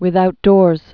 (wĭth-outdôrz, wĭth-)